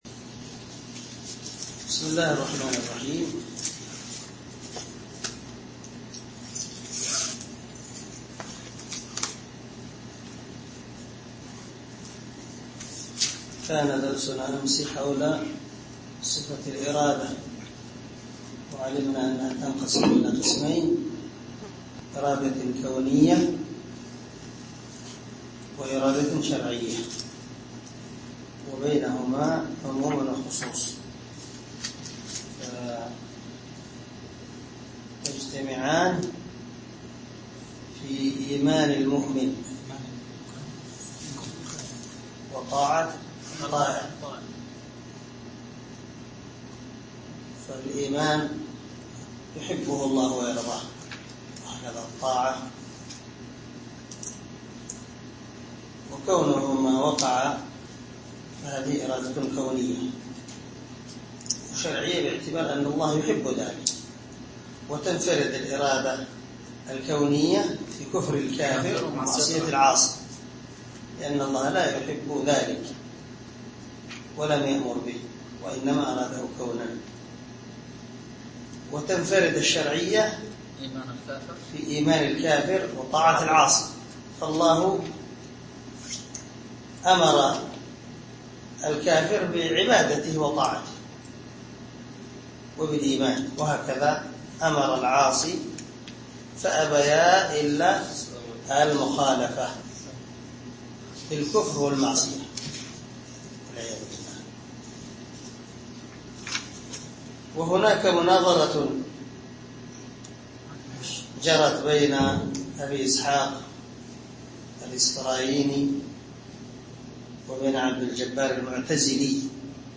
شرح العقيدة الواسطية للعلامة محمد بن خليل هراس رحمه الله – الدرس العشرون
دار الحديث- المَحاوِلة- الصبيحة.